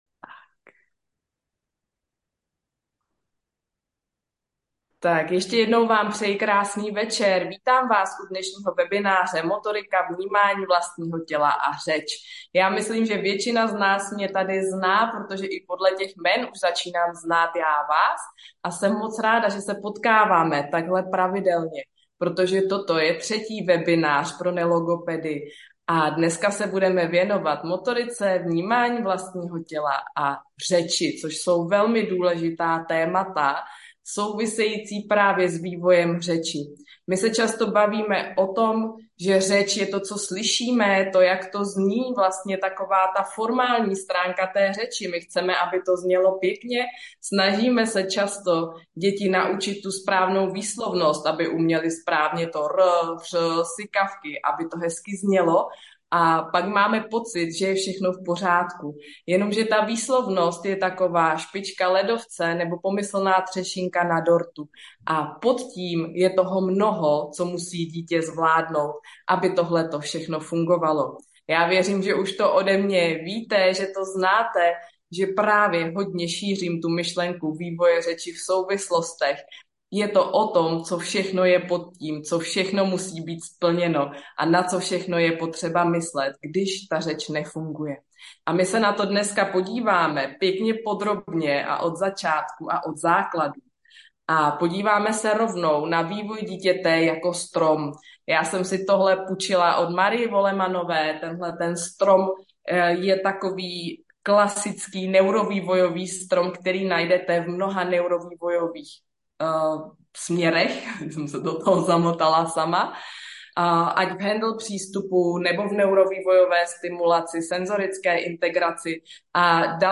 Webinář pro nelogopedy